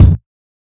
KICKPUNCH2.wav